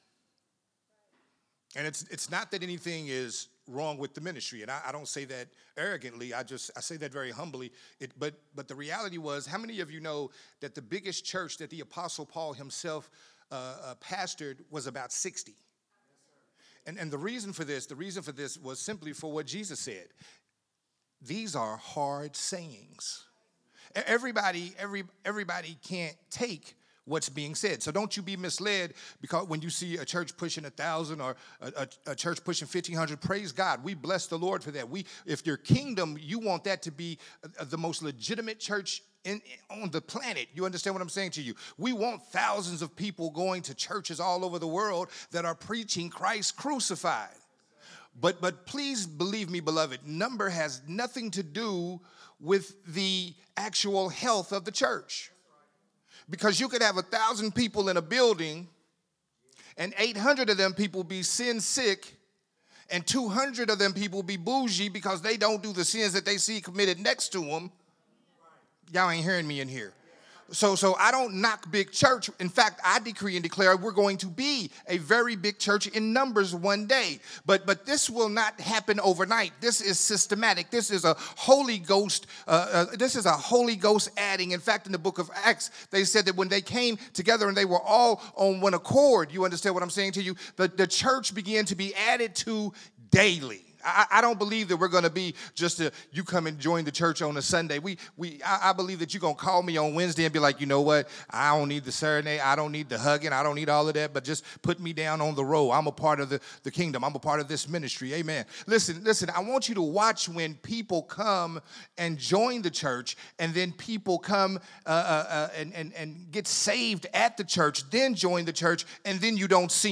Growth Temple Ministries